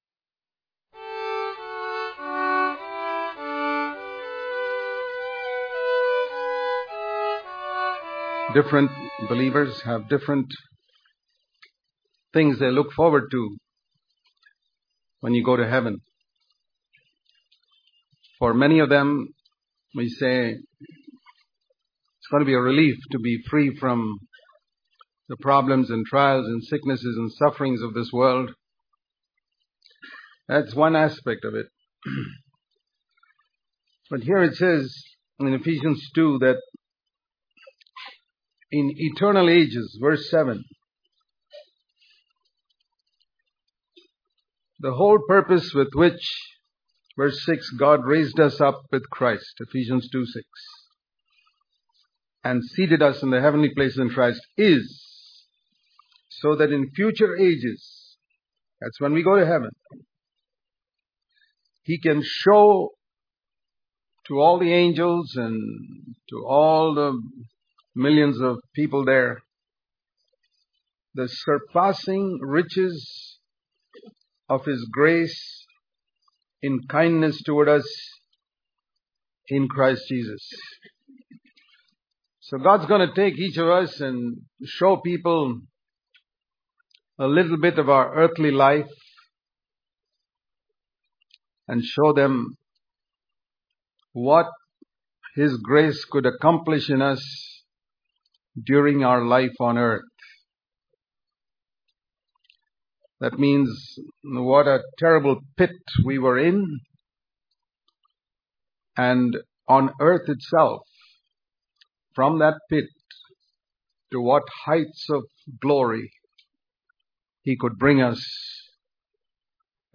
Daily Devotion